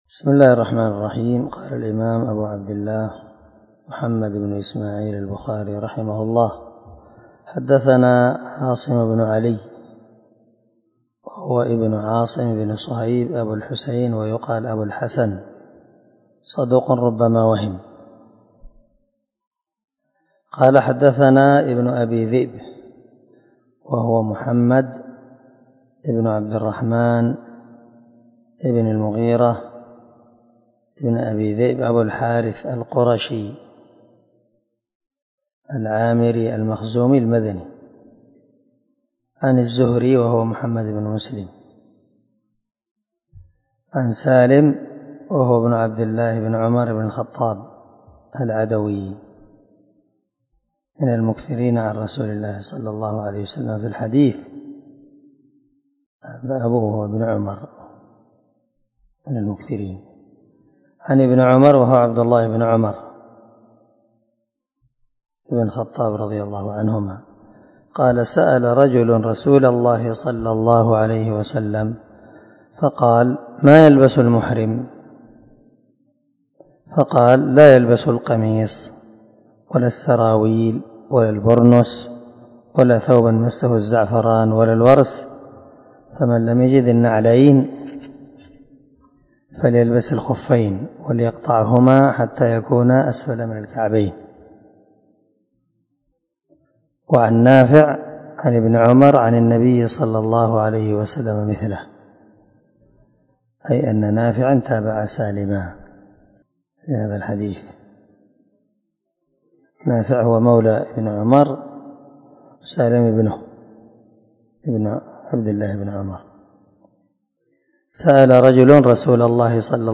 279الدرس 12 من شرح كتاب الصلاة حديث رقم ( 366 ) من صحيح البخاري